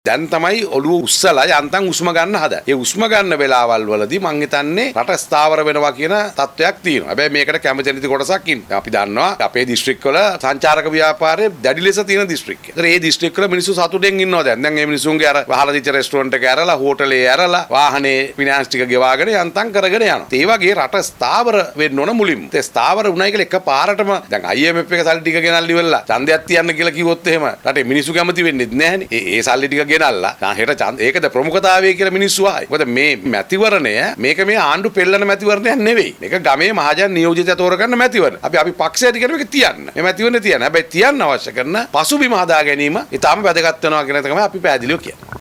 ඔහු මේ බව සදහන් කලේ ඊයේ පොදුජන පෙරමුණ පක්ෂ මුලස්ථානයේ පැවති මාධ්‍ය හමුවකට එක් වෙමින්.